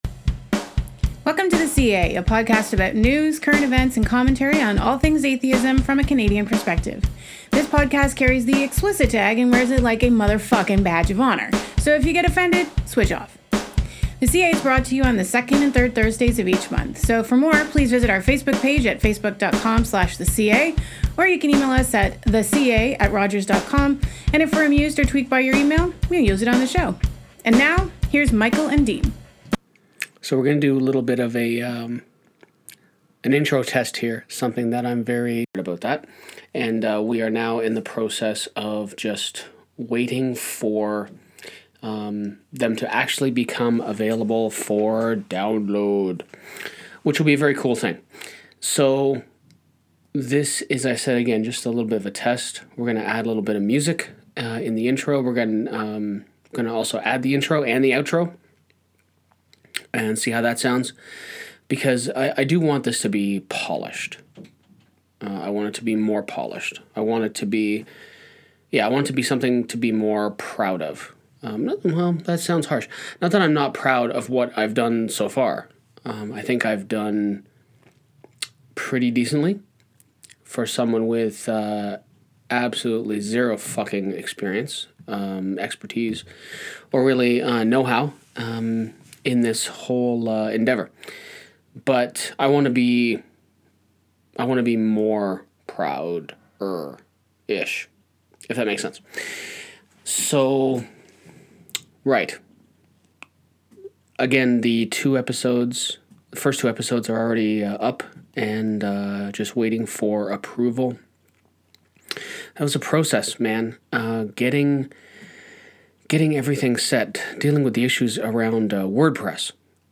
In this short episode, I just talk about trying to get better. I add a bit of music to enhance the voice of my lovely wife & that’s about all.